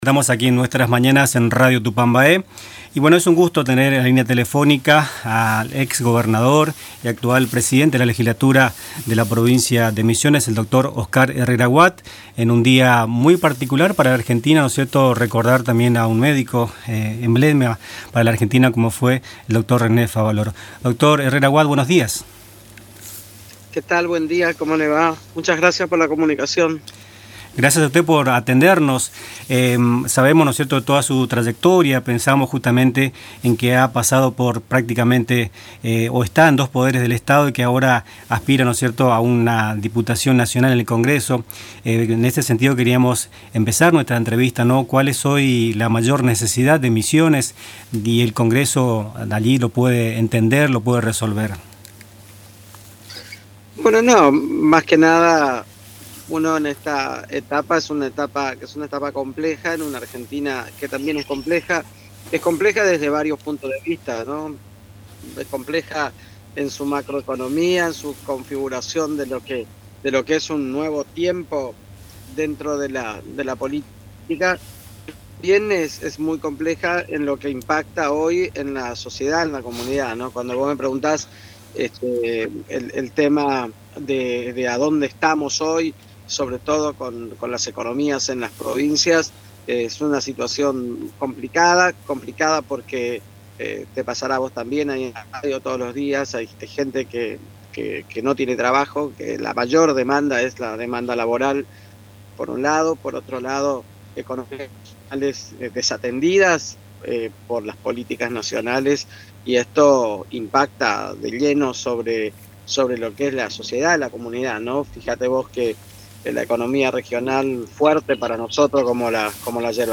Entrevista al candidato a diputado nacional (FR), Oscar Herrera Ahuad, actual presidente de la Legislatura de Misiones - Radio Tupambaé
El actual presidente de la Cámara de Representantes de Misiones y candidato a diputado nacional por el Frente Renovador, Oscar Herrera Ahuad, fue entrevistado en el programa “Nuestras Mañanas”, donde analizó distintos aspectos de la realidad provincial y nacional en el marco de su campaña rumbo a octubre.